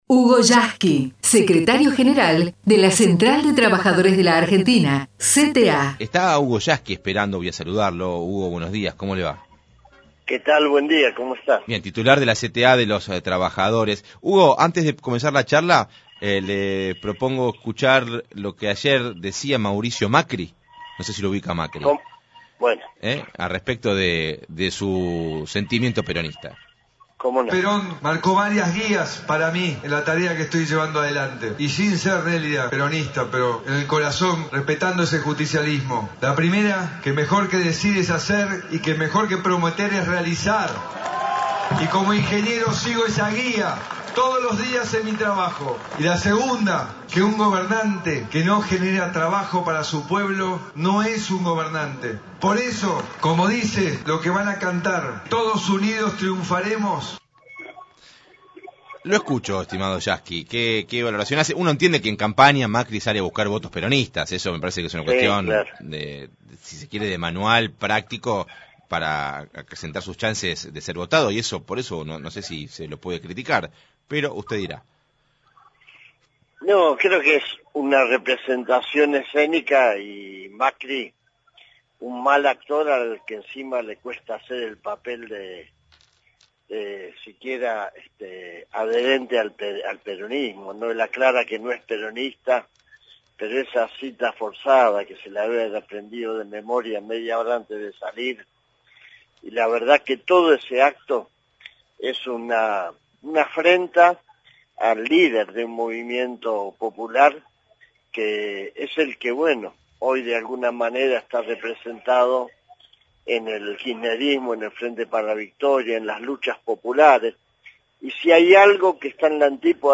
El Secretario General de la Central de Trabajadores de la Argentina (CTA) entrevistado en la Radio Pública